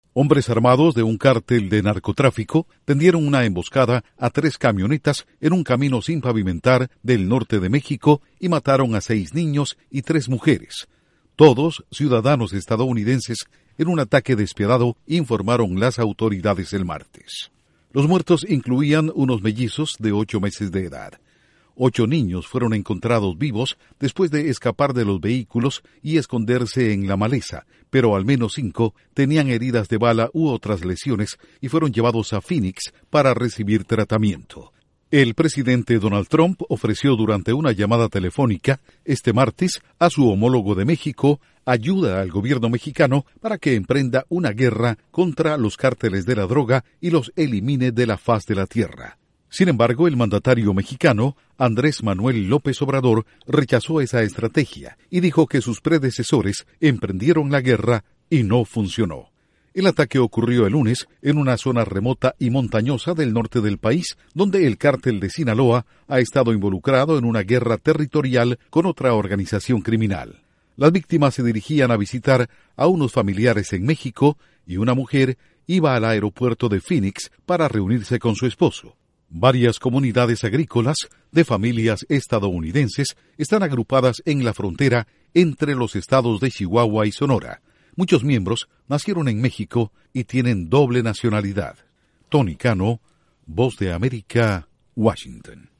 Trump ofrece ayuda a México tras asesinato de nueve estadounidenses en emboscada de cártel mexicano. Informa desde la Voz de América en Washington